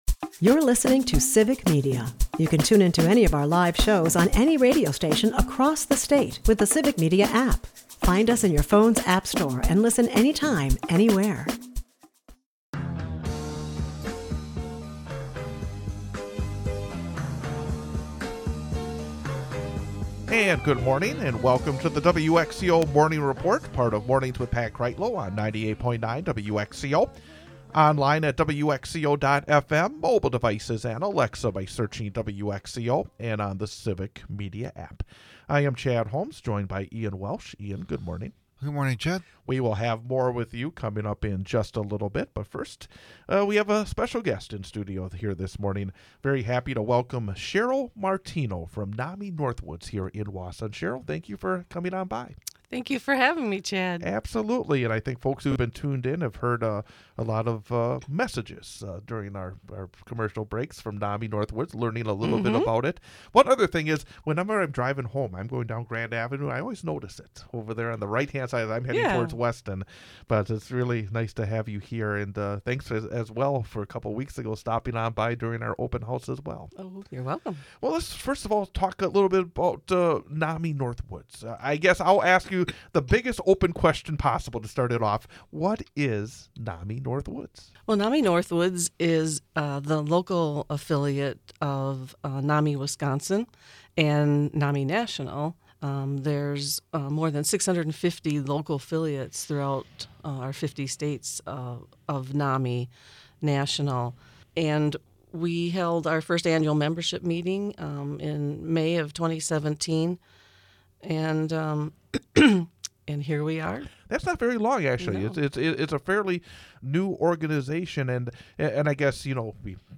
Today we bring back a recent discussion